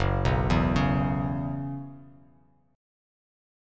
F#7sus4 chord